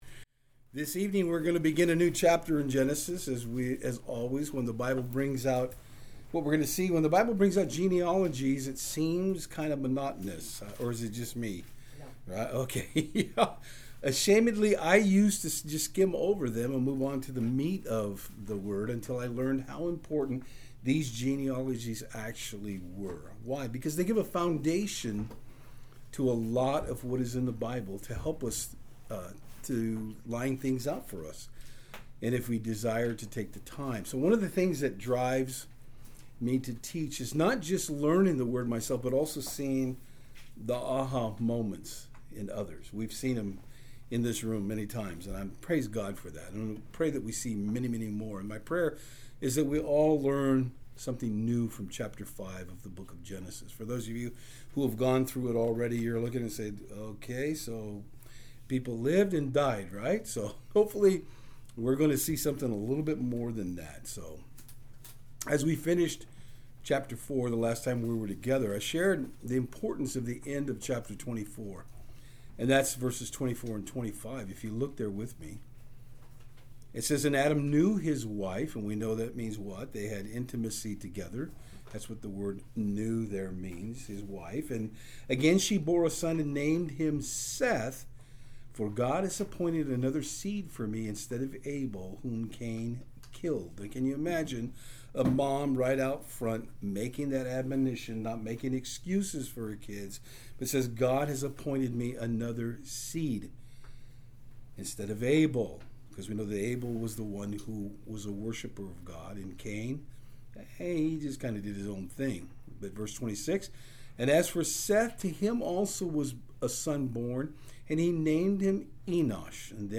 Genesis 5:1-32 Service Type: Saturdays on Fort Hill In this study tonight we will be looking at how Through the line of Seth his Father Adams line is mentioned historically.